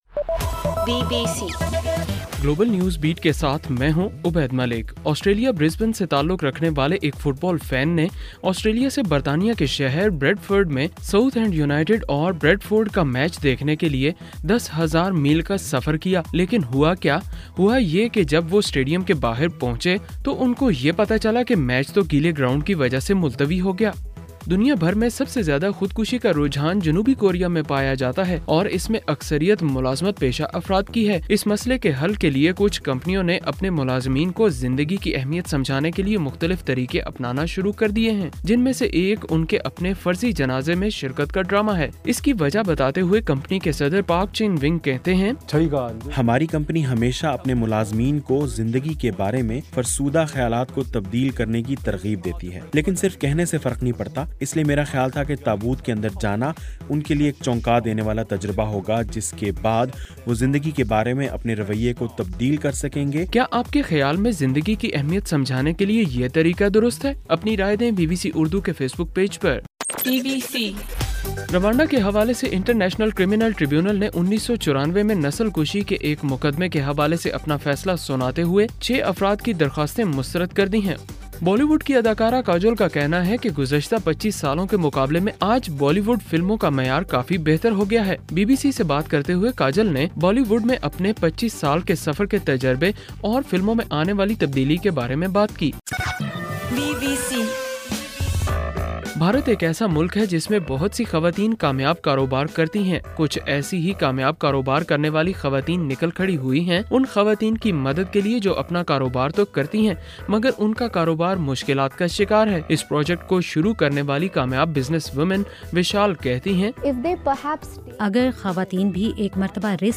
دسمبر 14: رات 11 بجے کا گلوبل نیوز بیٹ بُلیٹن